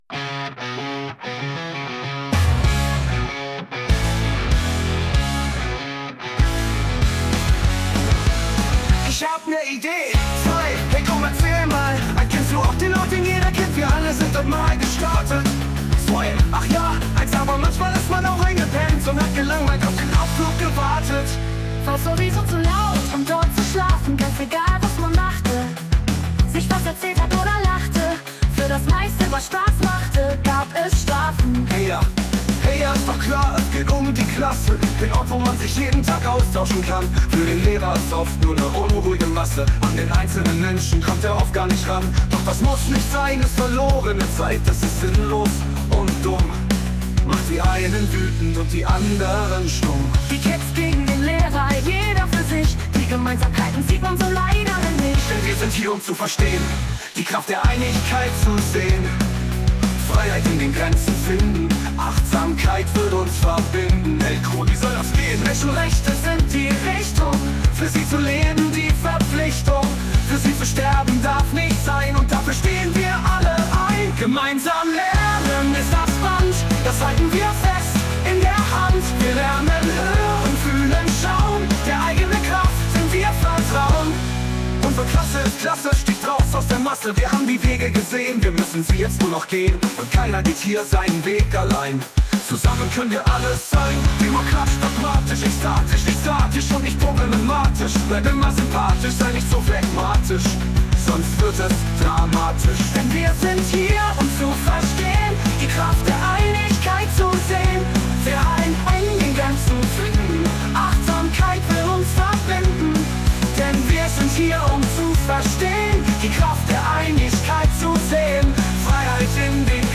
HappyPunk mixed Vocals